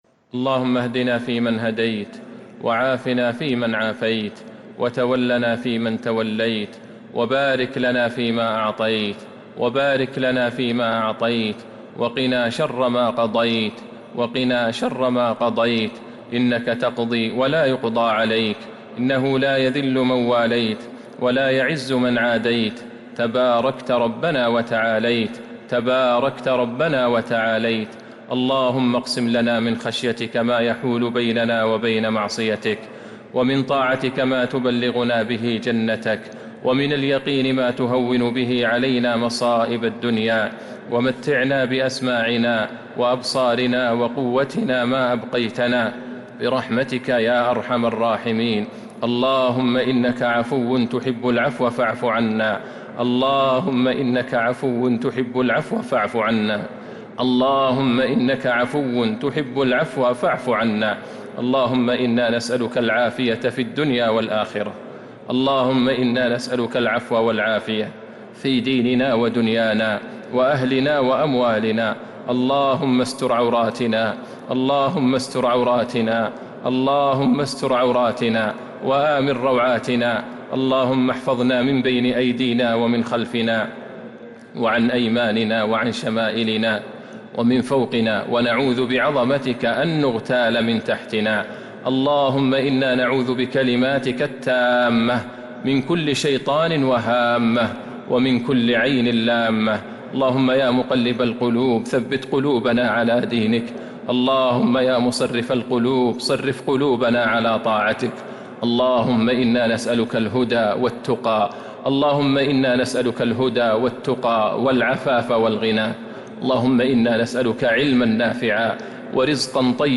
دعاء القنوت ليلة 21 رمضان 1442هـ | Dua for the night of 21 Ramadan 1442H > تراويح الحرم النبوي عام 1442 🕌 > التراويح - تلاوات الحرمين